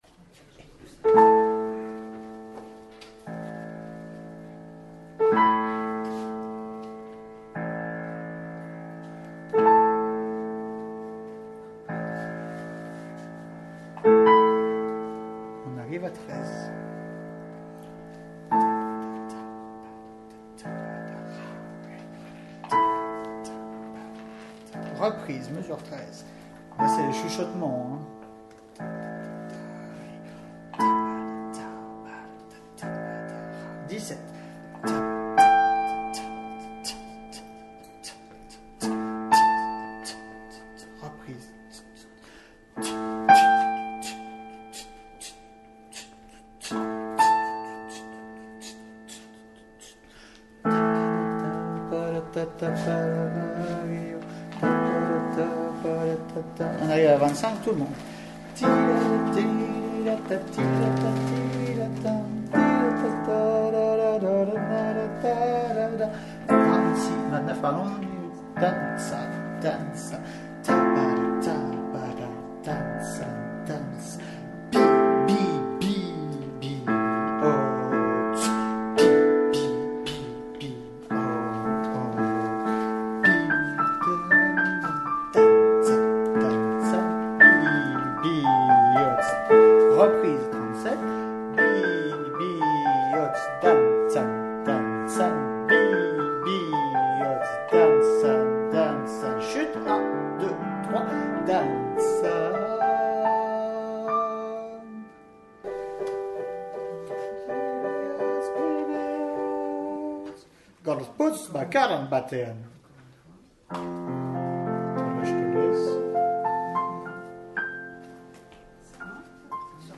bi-bihotz-piano